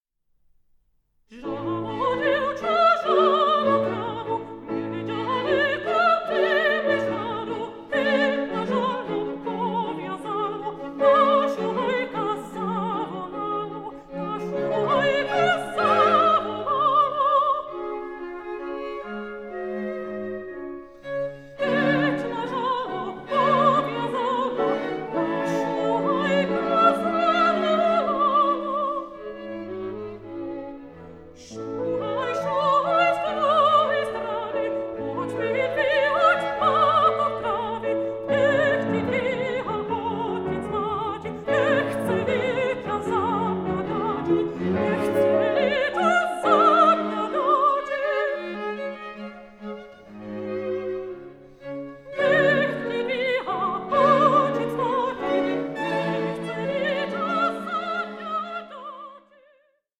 AN EVENING OF INTIMATE SONGS AMONG FRIENDS
mezzo-soprano